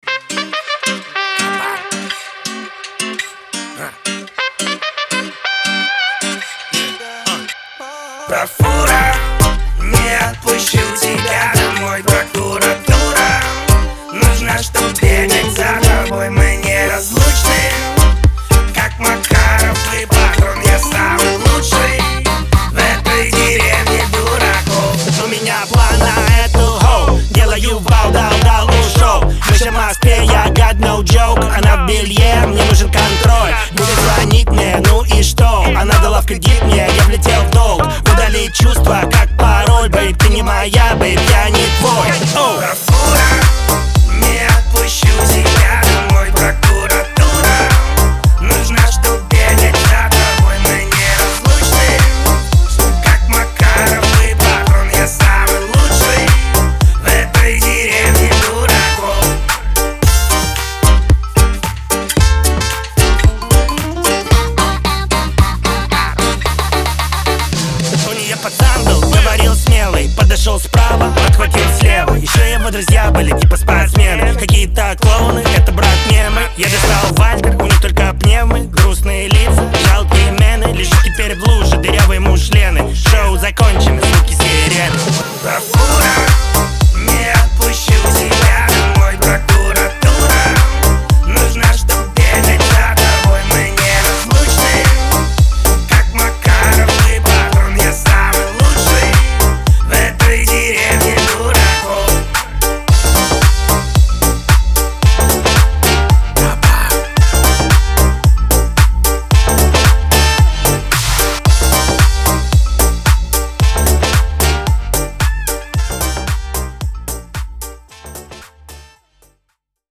ХАУС-РЭП , дуэт